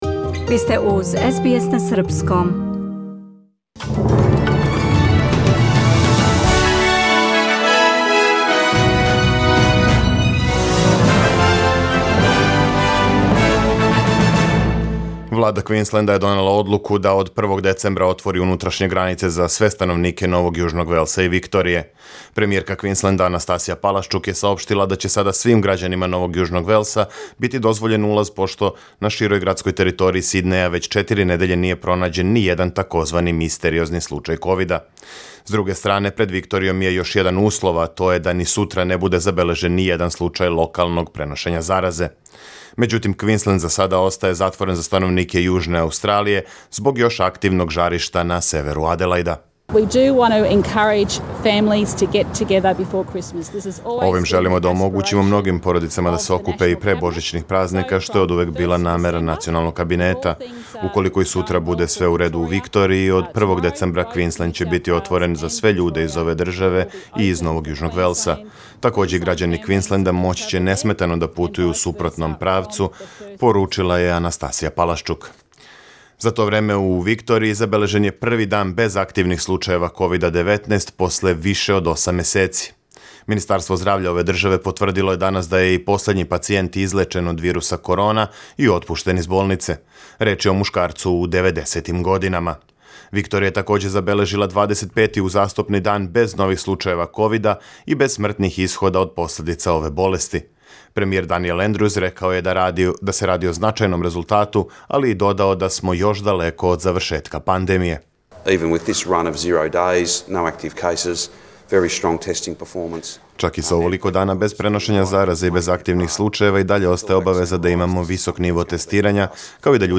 Serbian News Bulletin Source: SBS Serbian